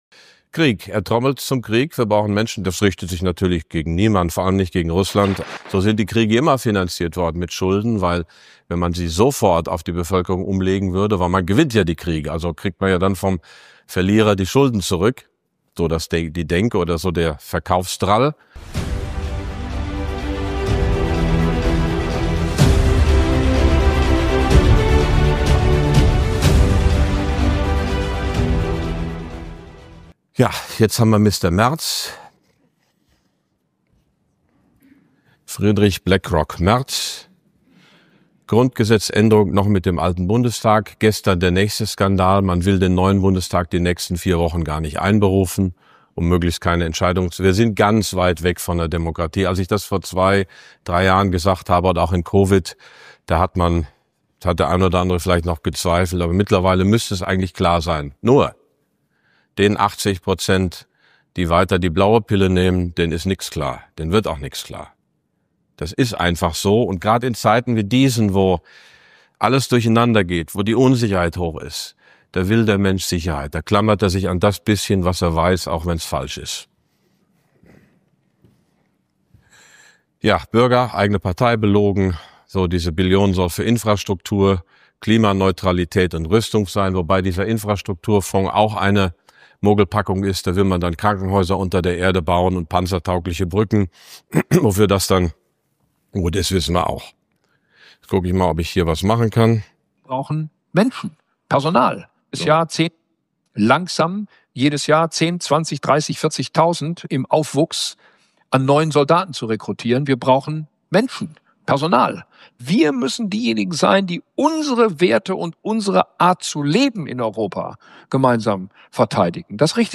In diesem Vortrag von Prof. Dr. Max Otte wird die Mogelpackung „Sondervermögen“ entlarvt und gezeigt, wer in Deutschland wirklich die Fäden zieht. Eine schonungslose Analyse zur drohenden Kriegswirtschaft und was das für uns alle bedeutet. Hinweis: Dieser Vortrag wurde beim Kapitaltag im April 2025 aufgezeichnet.